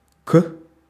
Ääntäminen
IPA: /a/